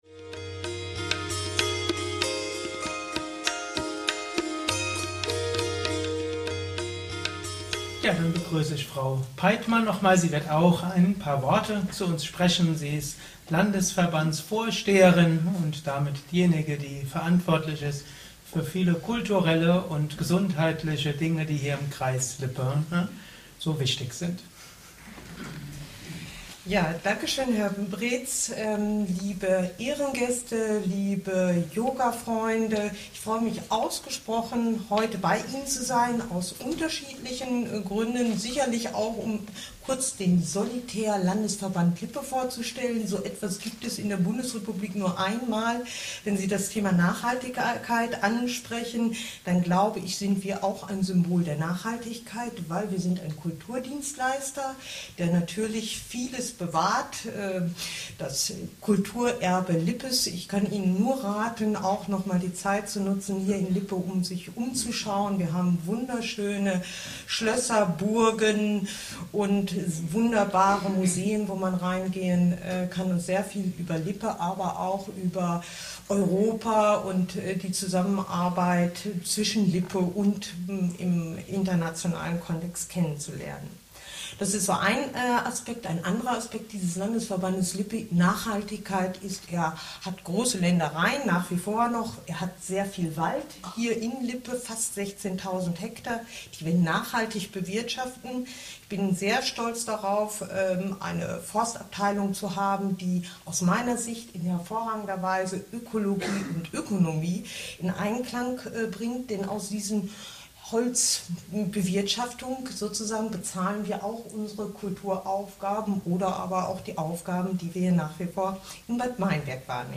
Eröffnungsrede Teil 3 mit Frau Peitmann - Business Yoga Kongress 2014
3_BusinessKongress_2014_Eroeffnungsrede_Peitmann_VorsteherindesLandesverbandsLippe.mp3